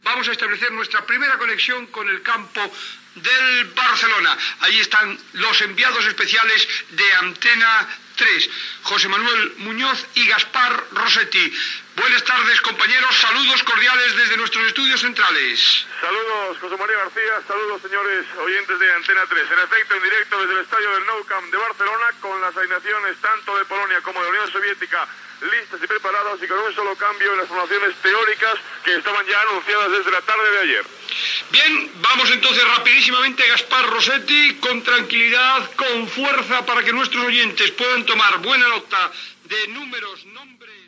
Connexió amb el Camp Nou de Barcelona en la prèvia del partit Polònia Unió Soviética de la Copa del Món de Futbol 1982
Esportiu